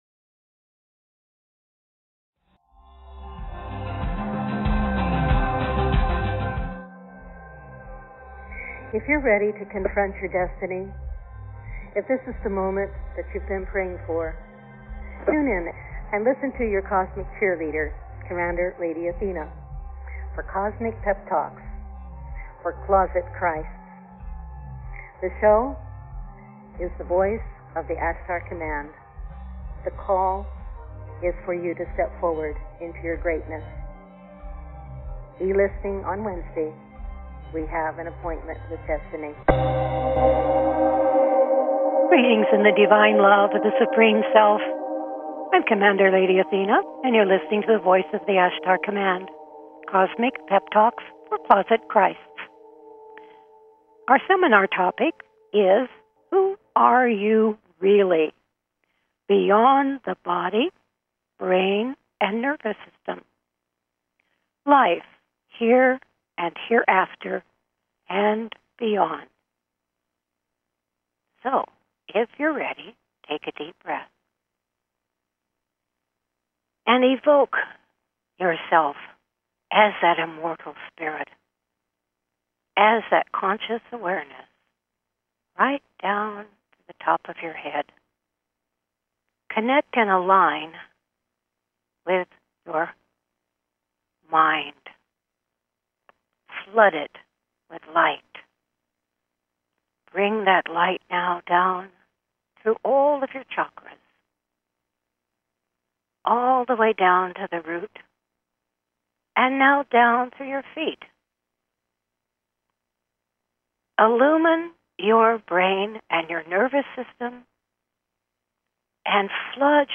Talk Show Episode, Audio Podcast, The Voice of the Ashtar Command-Cosmic Pep Talks For Closet Christs and WHO ARE YOU REALLY BEYOND THE BODY-BRAIN & NERVOUS SYSTEM?